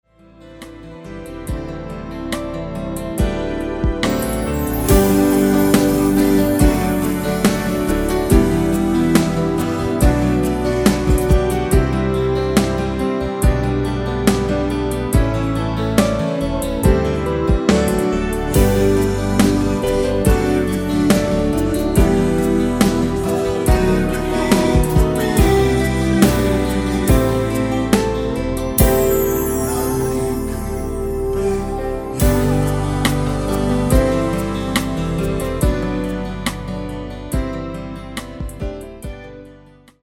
코러스 포함된 MR 입니다.(미리듣기 참조)
◈ 곡명 옆 (-1)은 반음 내림, (+1)은 반음 올림 입니다.
앞부분30초, 뒷부분30초씩 편집해서 올려 드리고 있습니다.